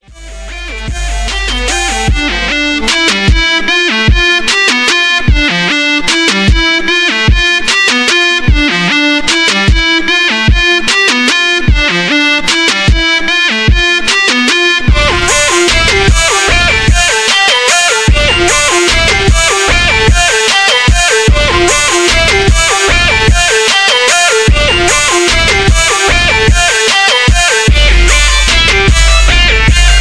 Untagged version of the beat